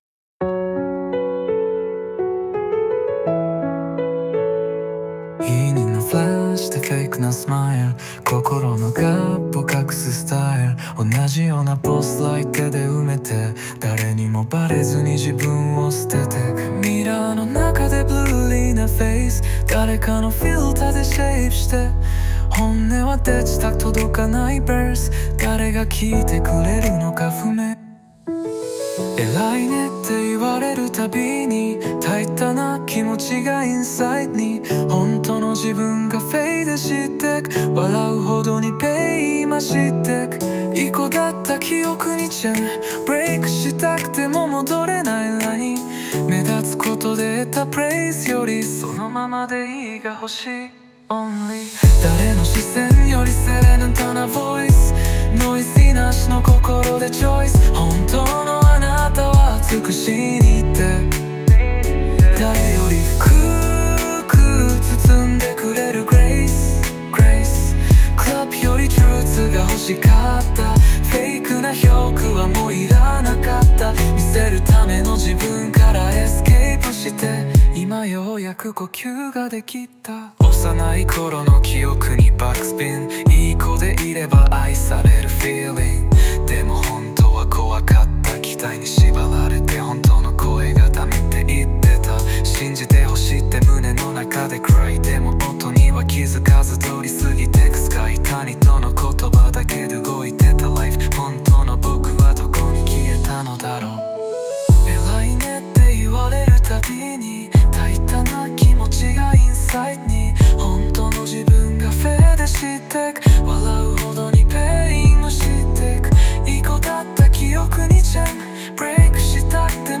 この楽曲『Lost Likes』は、そんな承認欲求の重圧とそこからの解放をテーマにした、Cloud Rapスタイルのオリジナル曲です。